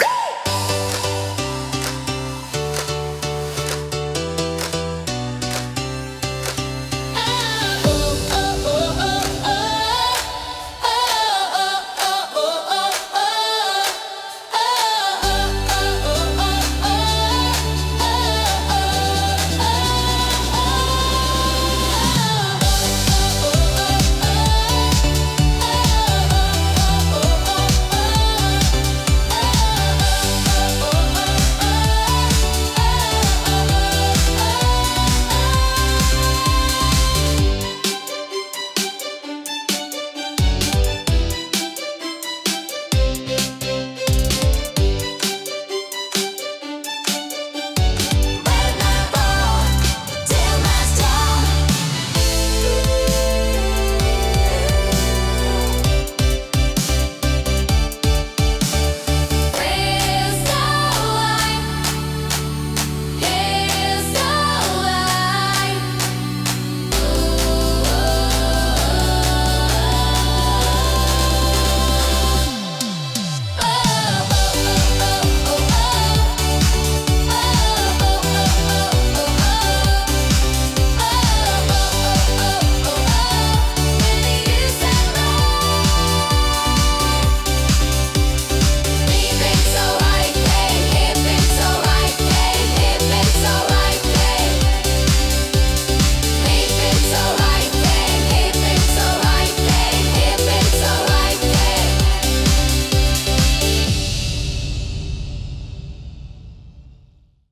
聴く人の気分を高め、集中力とパワーを引き出すダイナミックなジャンルです。